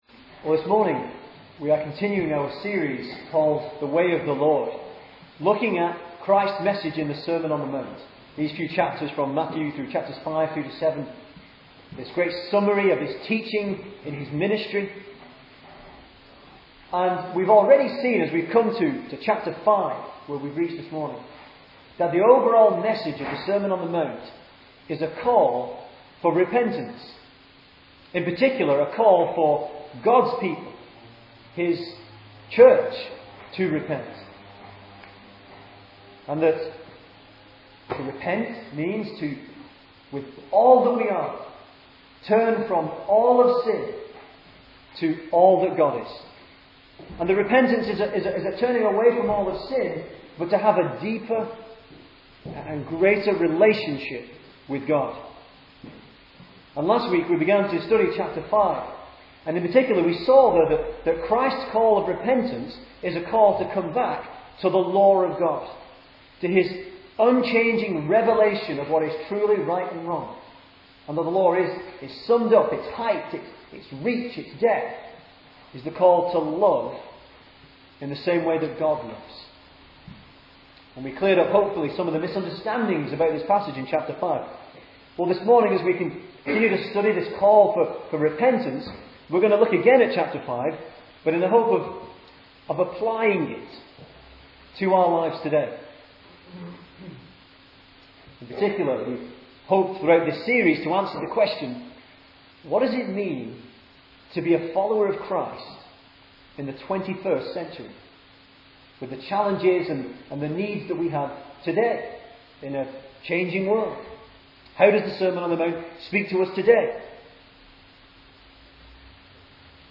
2010 Service Type: Sunday Morning Speaker